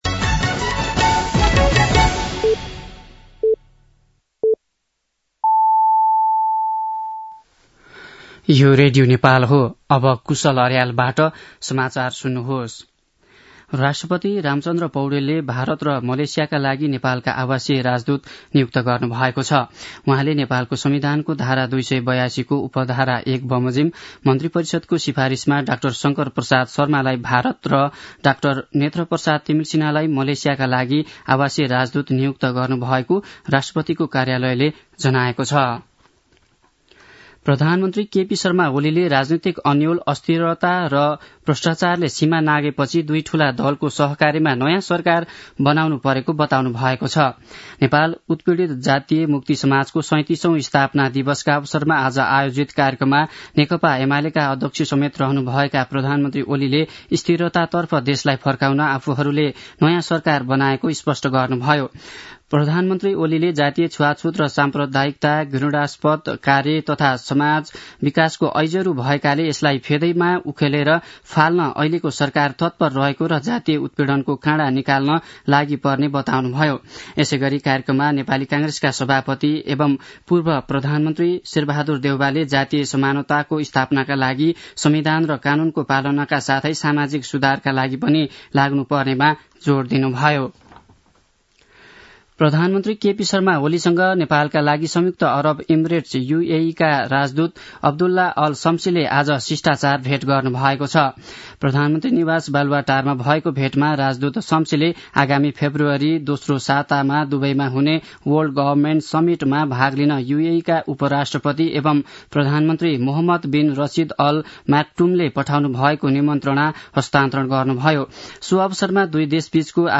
दिउँसो ४ बजेको नेपाली समाचार : ४ पुष , २०८१
4-pm-nepali-news-2.mp3